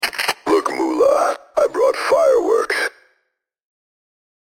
CharacterSoundEffect_BoomForMoolah.mp3